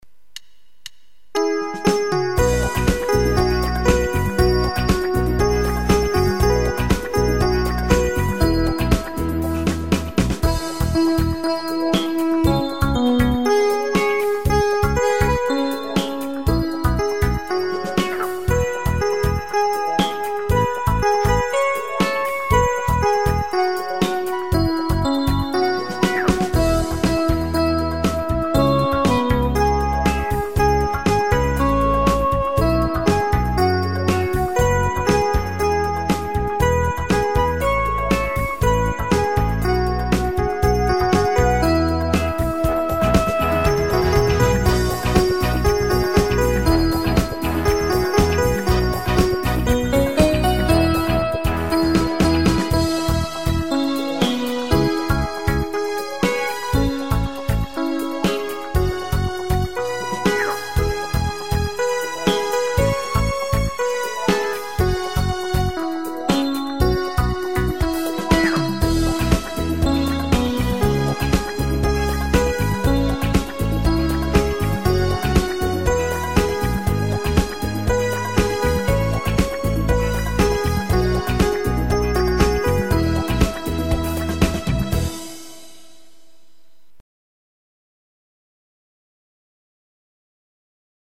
Nhạc không lời chủ đề nước và mùa hè